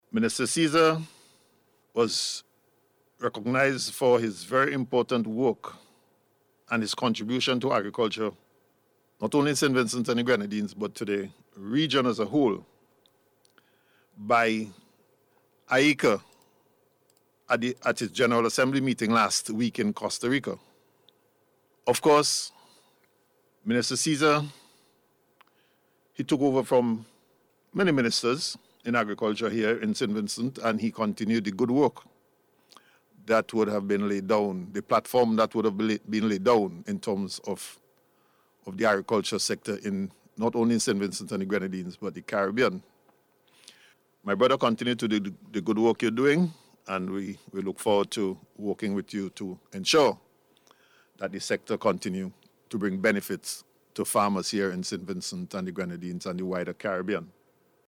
The commendation came from Acting Prime Minister Montgomery Daniel while speaking on NBC Radio this morning.